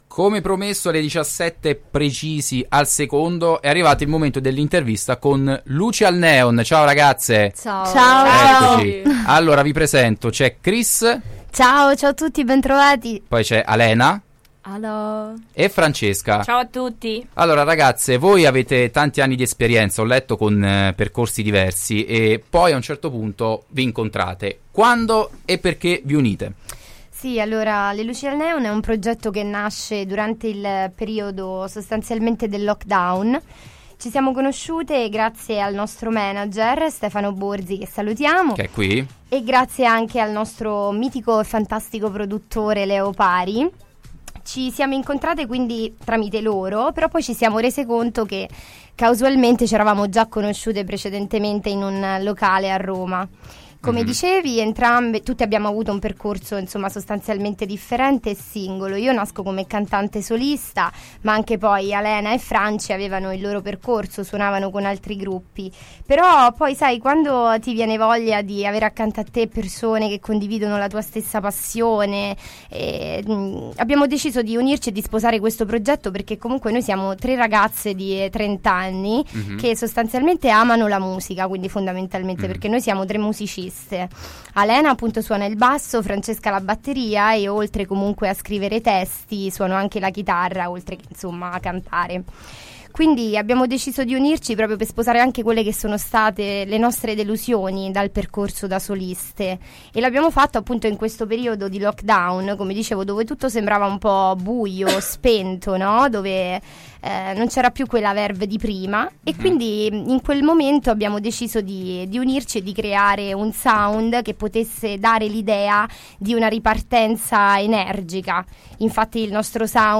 Le tre musiciste sono intervenute ai microfoni di Radio Città Aperta per parlare della storia dietro il nuovo singolo e dei loro progetti futuri.
Intervista-LUCI-AL-NEON.mp3